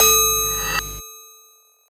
SOUTHSIDE_percussion_bellvedere_A.wav